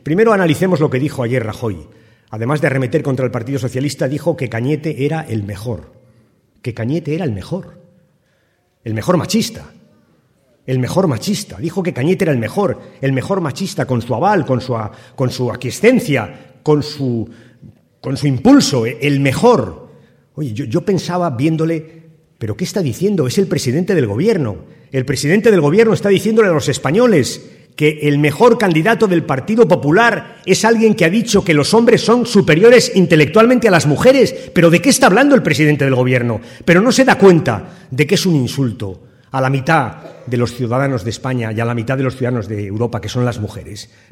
En el Teatro Victoria, de Talavera de la Reina, donde 600 militantes y simpatizantes socialistas han acompañado a Rubalcaba, y donde han intervenido además el líder de los socialistas castellano-manchegos, Emiliano García Page, y el candidato al Parlamento Europeo Sergio Gutiérrez, el Secretario General del PSOE ha recordado además que Cospedal se estrenó como presidenta de Castilla-La Mancha quitando las ayudas a las mujeres víctimas de la violencia de género, “precisamente las mujeres que más ayuda necesitan”.